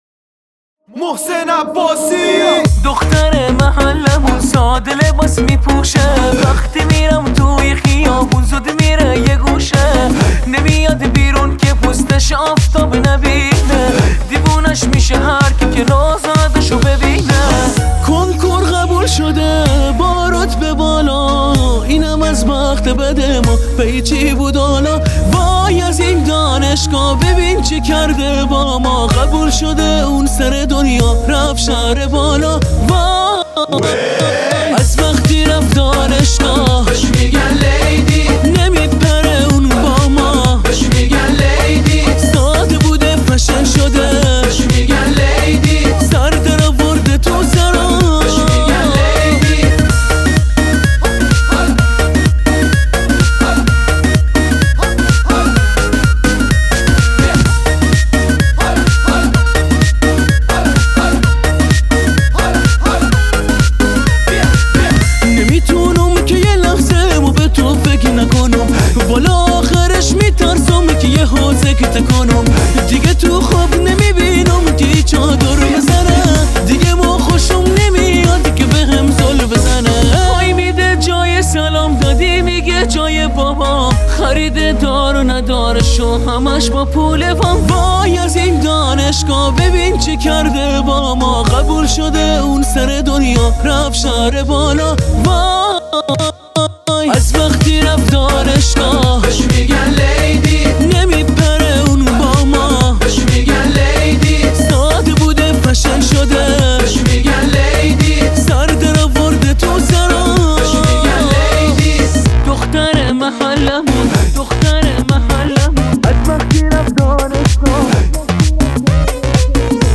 چند تا آهنگ وحشتناک قری و شاد
یه موزیک وحشتناک شاد هندی ازون دوپس دوپسیا براتون گذاشتیم